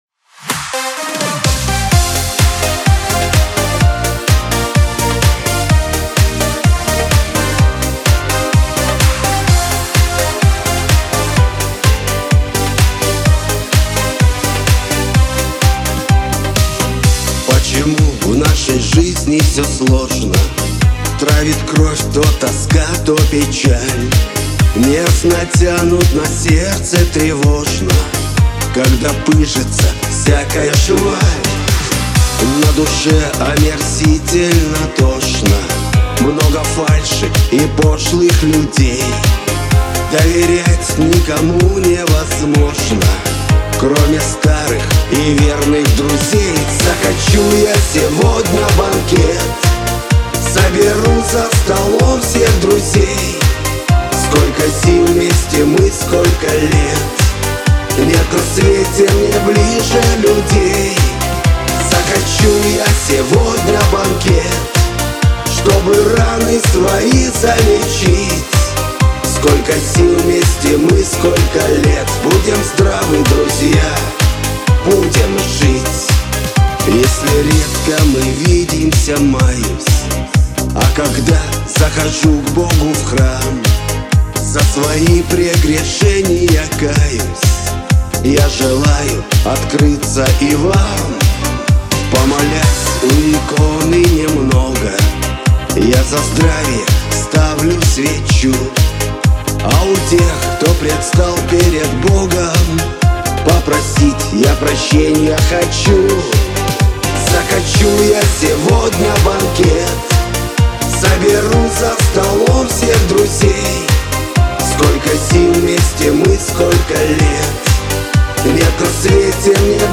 Трек размещён в разделе Русские песни / Рок / 2022.